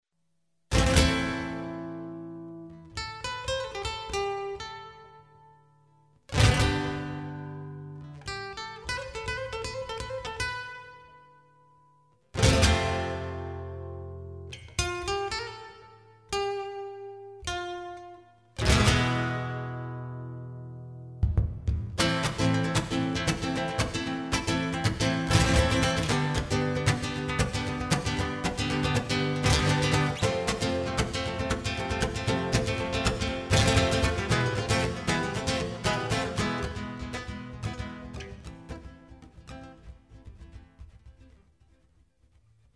Gitarrist
Spanisches Riff
spanisches_riff.mp3